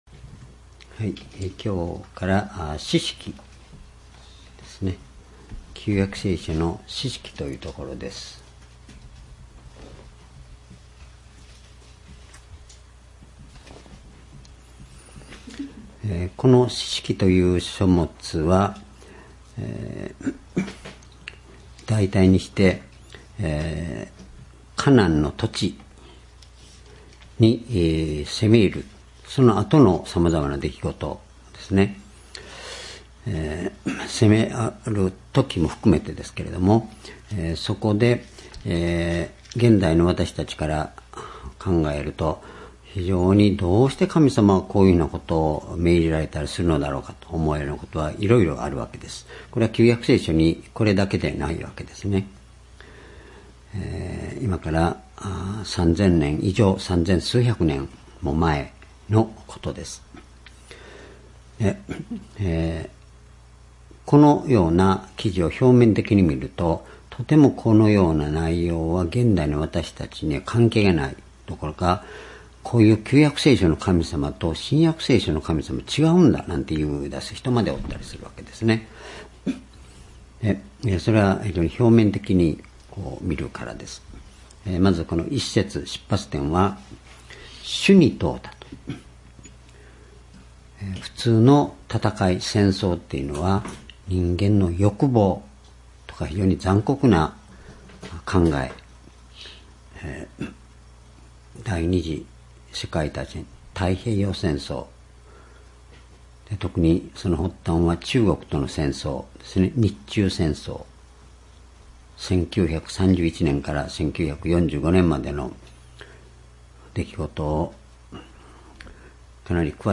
（主日・夕拝）礼拝日時 2019年9月3日 夕拝 聖書講話箇所 「主が共におられる」 士師記1章 ※視聴できない場合は をクリックしてください。